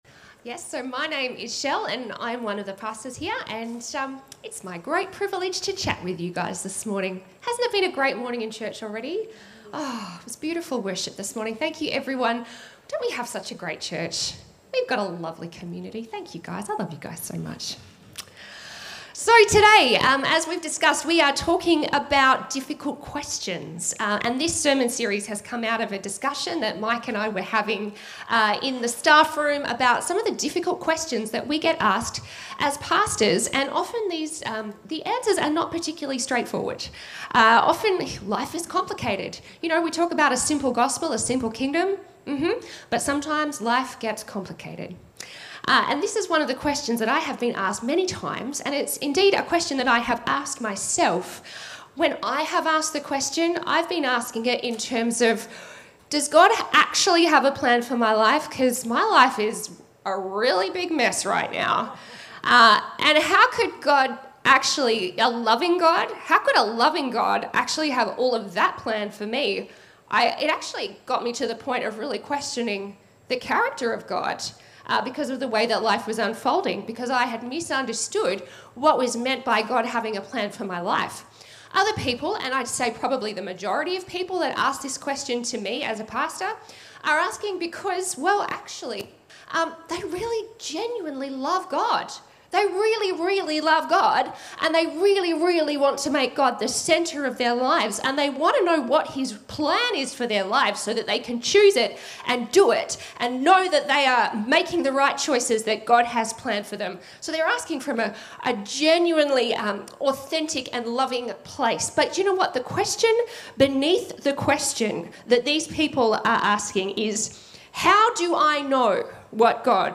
A message from the series "Difficult Questions."